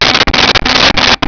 Sfx Klaxon2 Loop
sfx_klaxon2_loop.wav